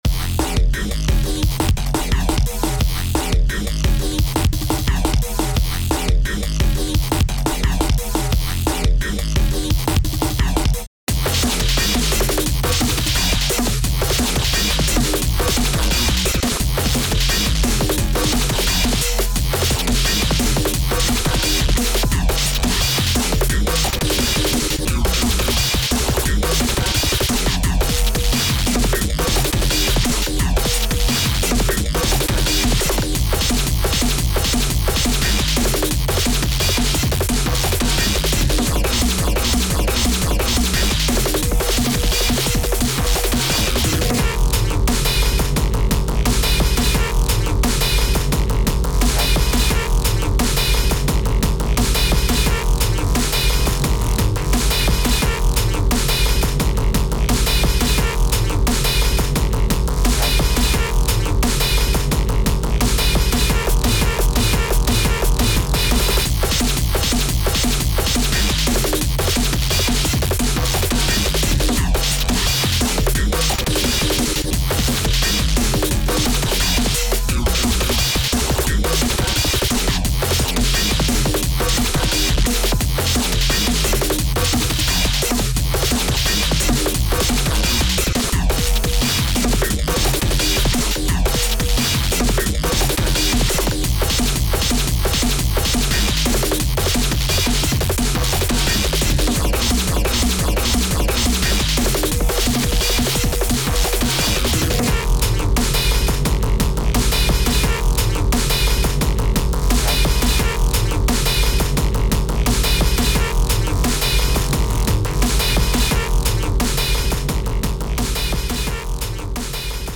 タグ: DnB EDM 変わり種 激しい/怒り 疾走感 コメント: 荒っぽいサウンドのブレイクコア楽曲。